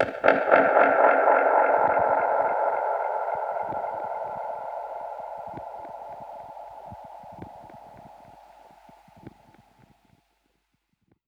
Index of /musicradar/dub-percussion-samples/85bpm
DPFX_PercHit_A_85-02.wav